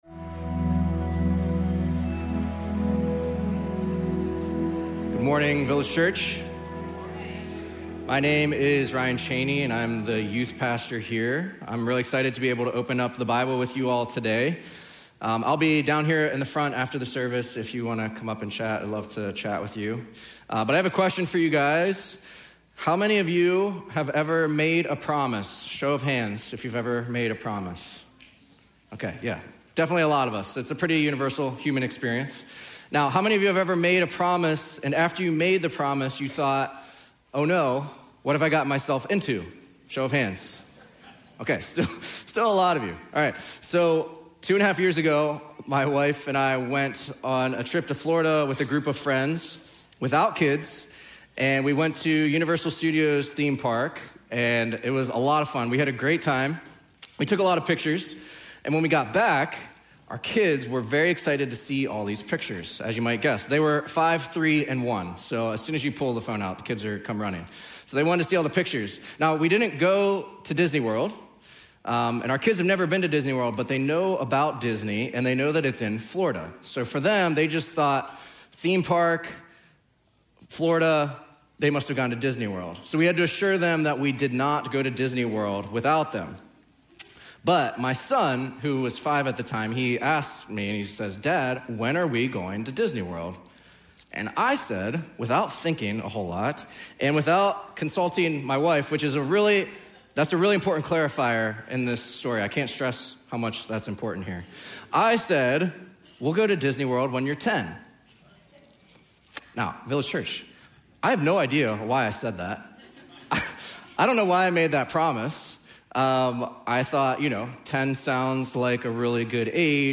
Village Church of Bartlett: Sermons Galatians Pt. 6: Promise and Dismantling False Anchors